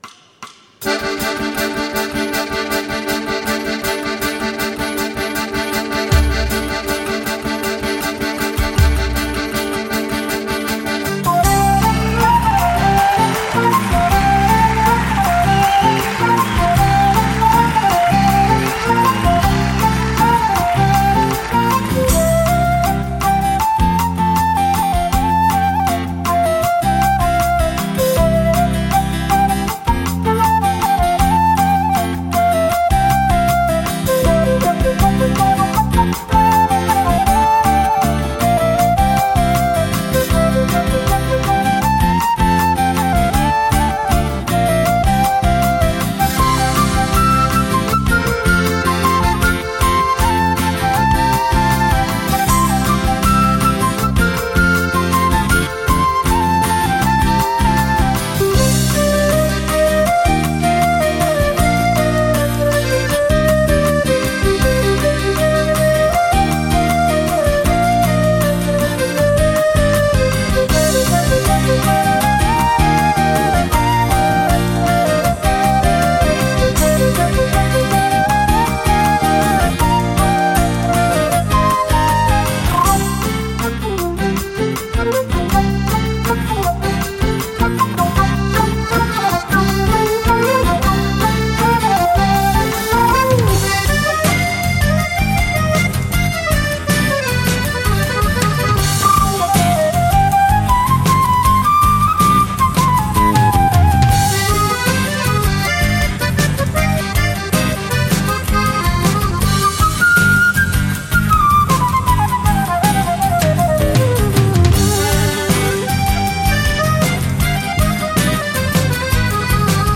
mad flautist
And the live version is a wee bit livelier than the studio.